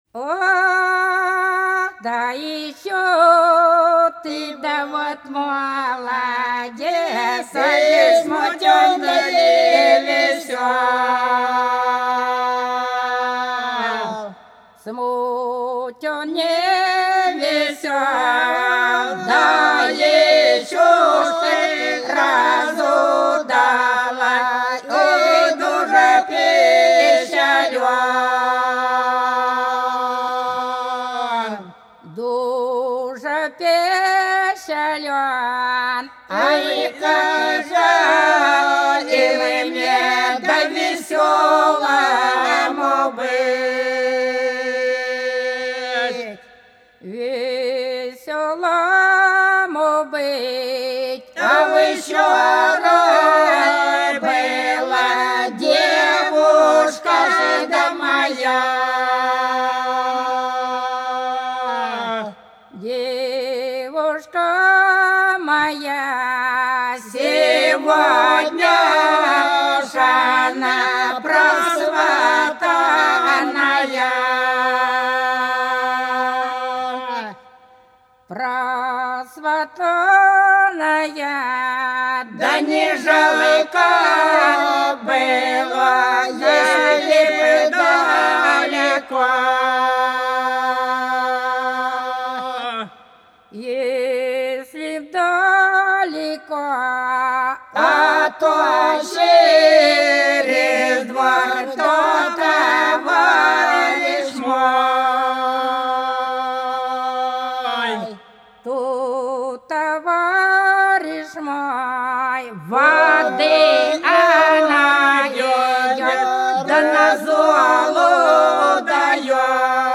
По-над садом, садом дорожка лежала Ох, да что ты, добрый молодец, смутен-невесёл - протяжная (с.Плёхово, Курская область)
07_Ох,_да_что_ты,_добрый_молодец,_смутен-невесёл_(протяжная).mp3